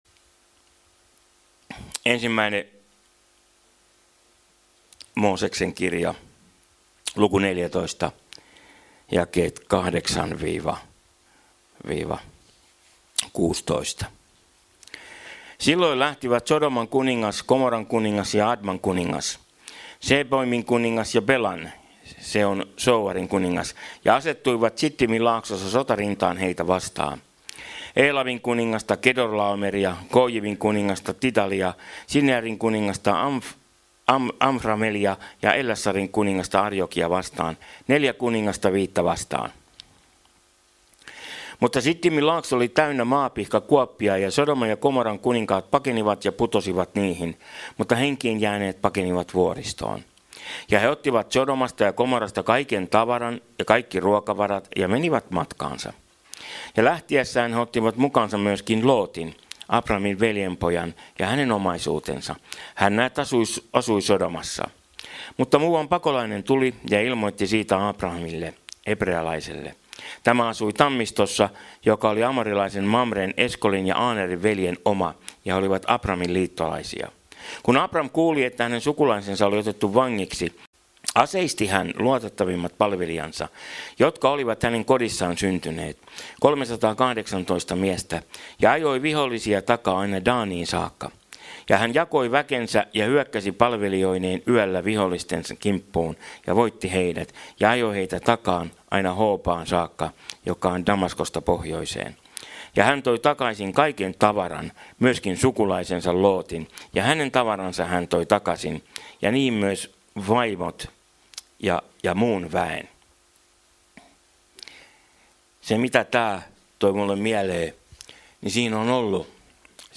Service Type: Jumalanpalvelus